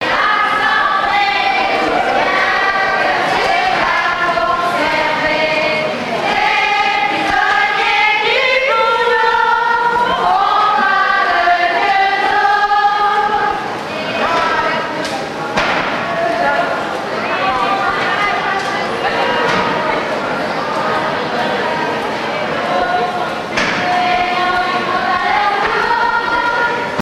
enquêteur Note Usine de conserves de sardines Amieux.
Pièce musicale inédite